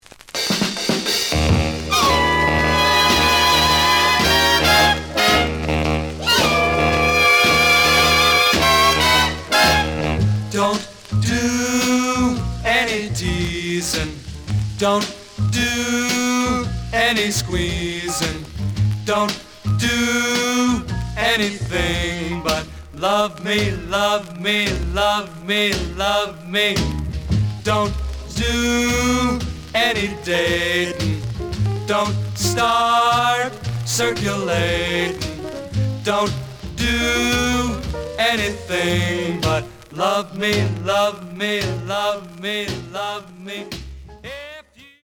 The audio sample is recorded from the actual item.
●Genre: Rhythm And Blues / Rock 'n' Roll
Some click noise on middle of B side due to a bubble.